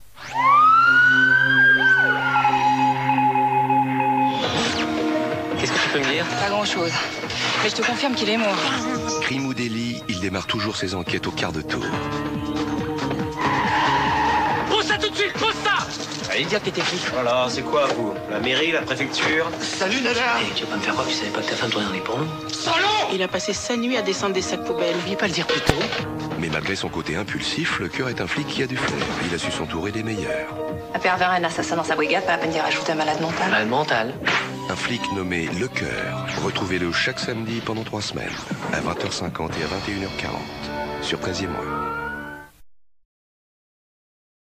Une bande annonce pour une série du samedi soir (1,2 Mo)